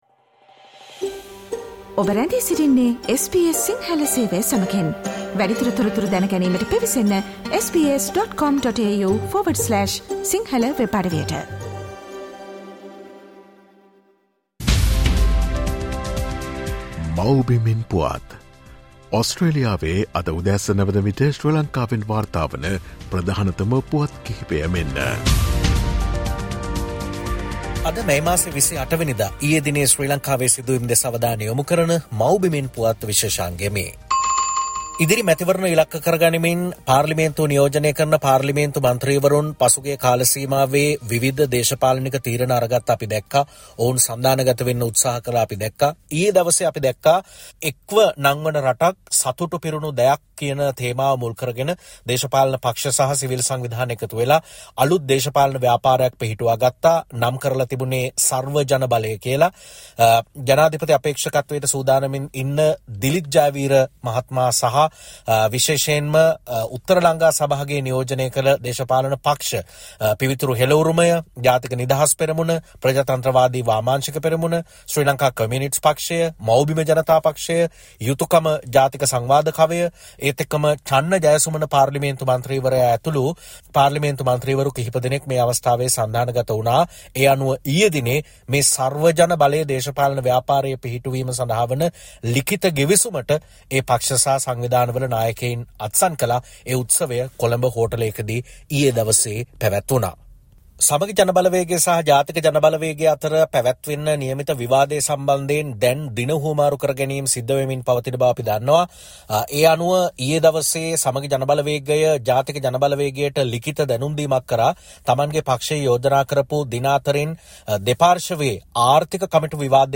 SBS Sinhala Service Brings “Homeland News”